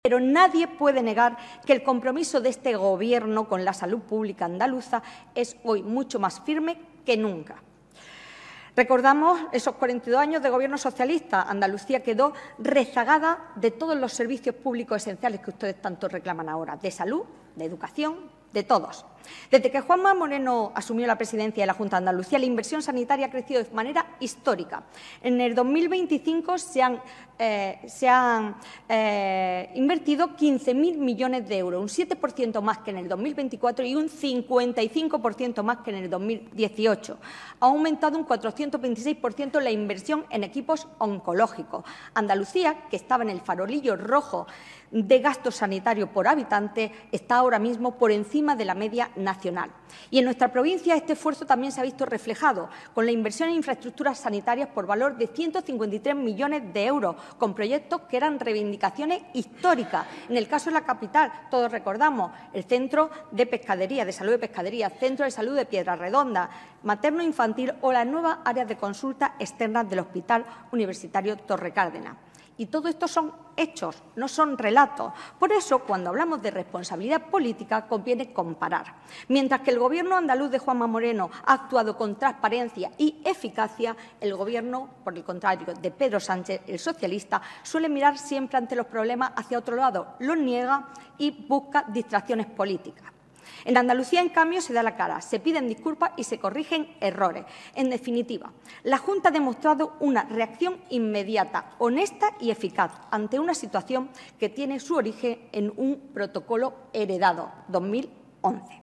La portavoz del Grupo Popular en el Ayuntamiento de Almería, Sacramento Sánchez, ha intervenido en el Pleno municipal para rechazar la moción presentada por el grupo socialista sobre el programa de detección precoz del cáncer de mama.